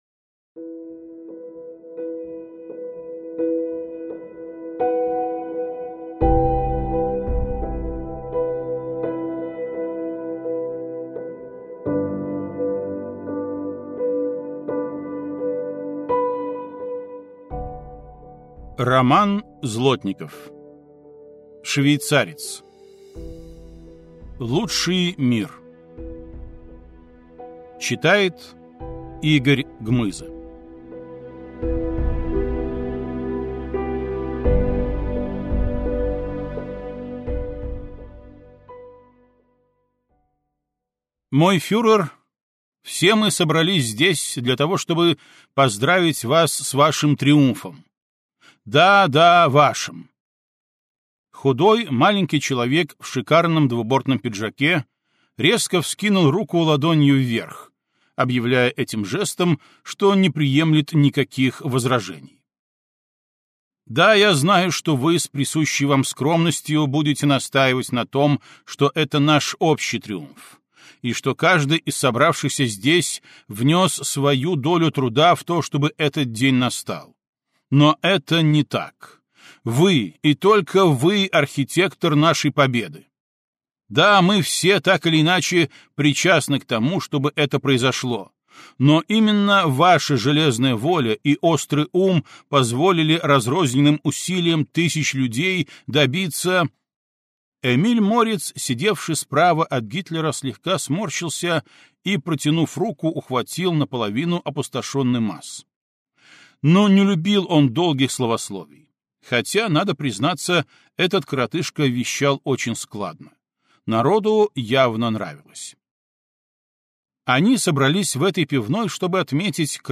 Аудиокнига Швейцарец. Лучший мир - купить, скачать и слушать онлайн | КнигоПоиск